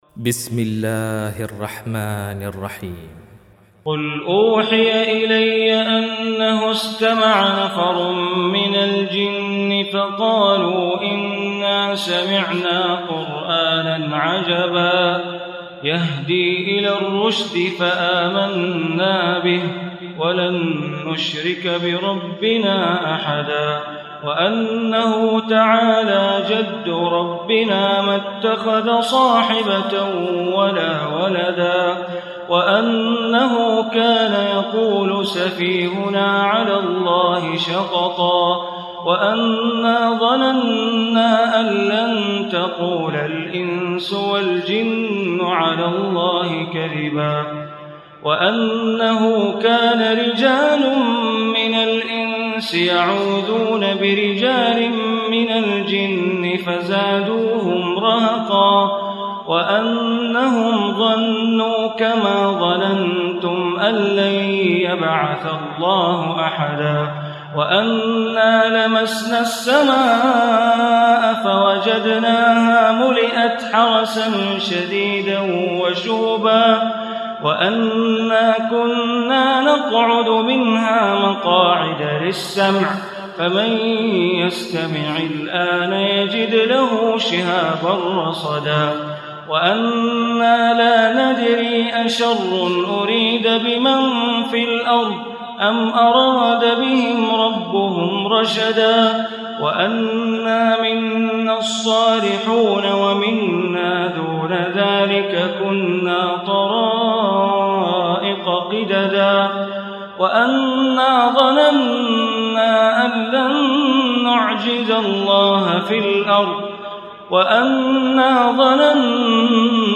Surah Jinn Recitation by Sheikh Bandar Baleela
Surah Jinn, listen online mp3 tilawat / recitation in Arabic recited by Imam e Kaaba Sheikh Bandar Baleela.